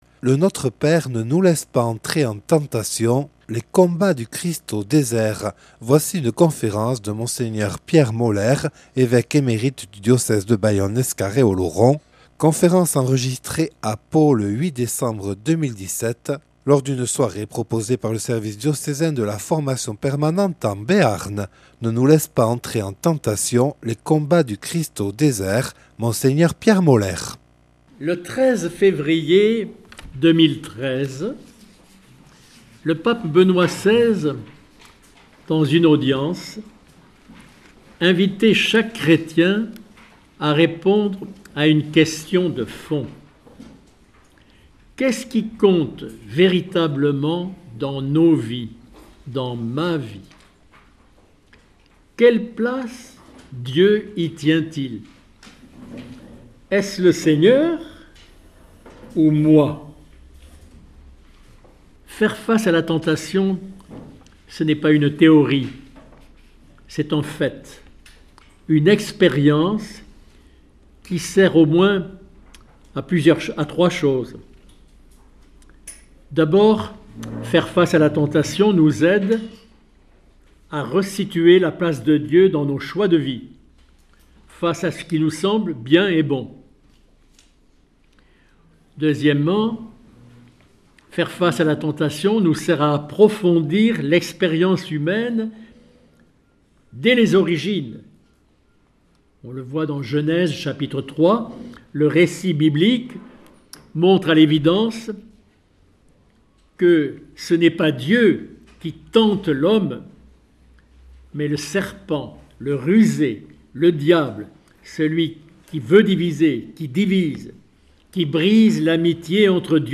par Mgr Pierre Molères, évêque émérite du diocèse de Bayonne, Lescar et Oloron. (Enregistré le 08/12/2017 à Pau lors d’une soirée proposée par le Service diocésain de la Formation Permanente).